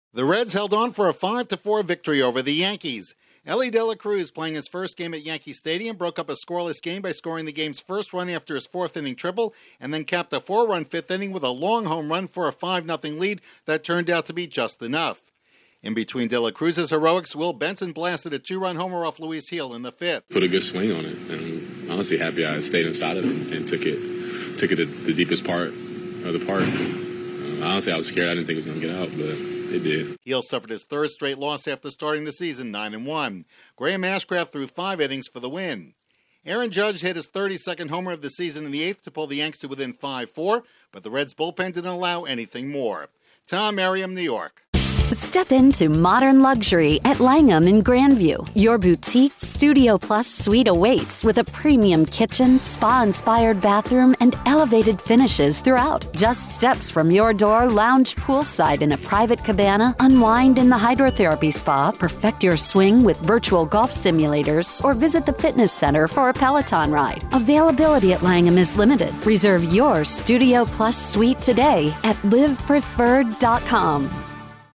The Reds grab a five-run lead before holding off the Yankees. Correspondent